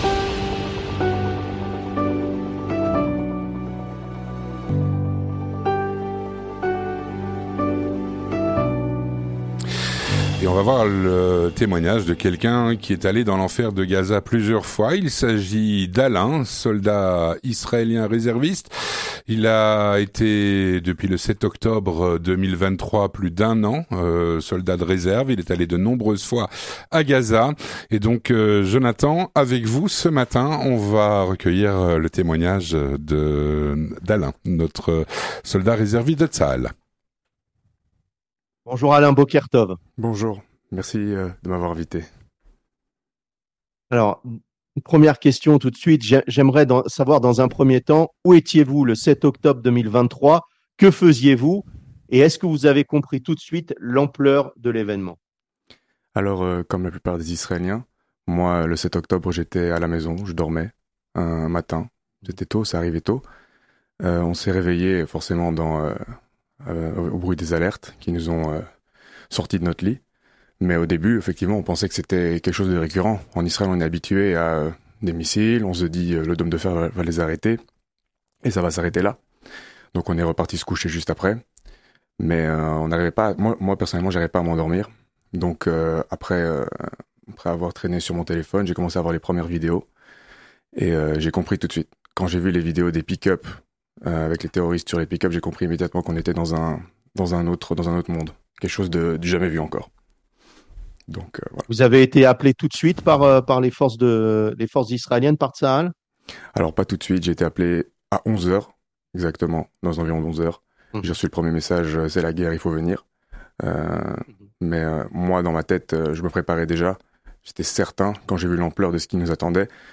Témoignage - Un soldat réserviste de Tsahal est allé plusieurs fois dans l’enfer de Gaza.